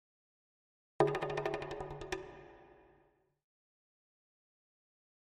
Jungle Drums In The Middle Of The Jungle - Slow Down Thin Hits 1